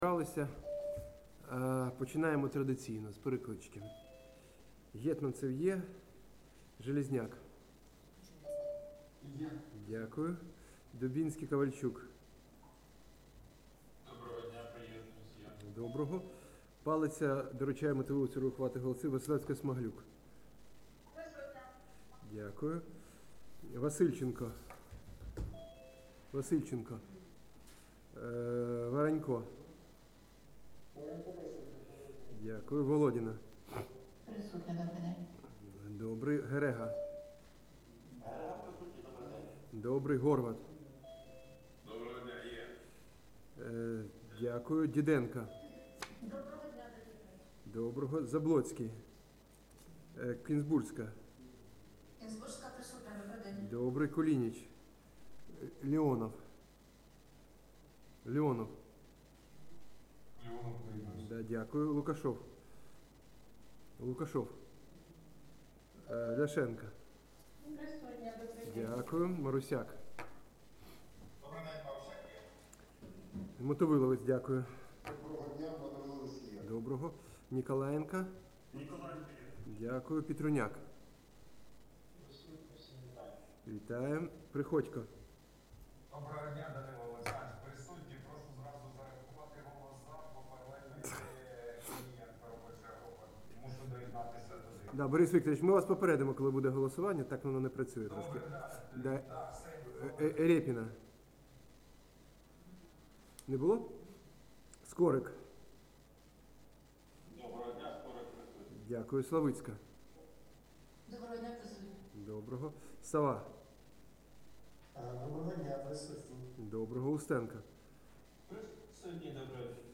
Головна Аудіо Аудіозаписи засідань і слухань Комітету Аудіозапис 26.12.2025 Опубліковано 29 грудня 2025, о 10:55 Назва файлу - Аудіозапис 26.12.2025